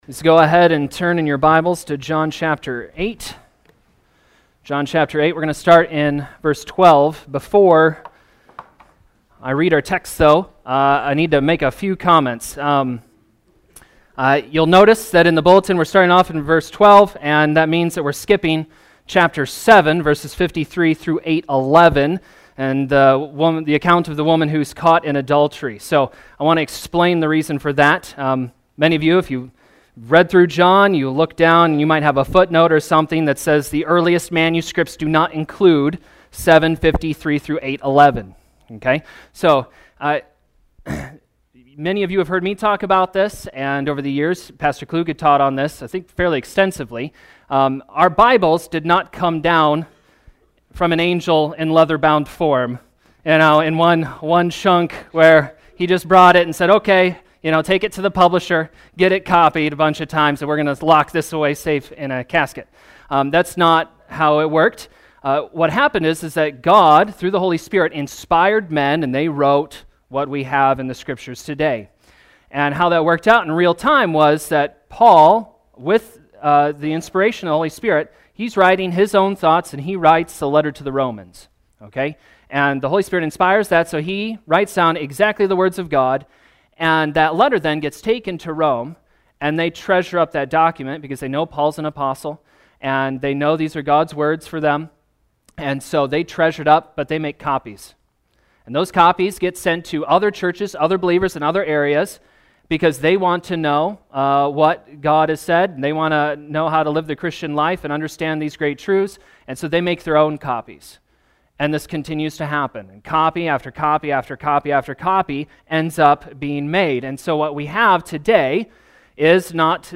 Gospel of John Sermons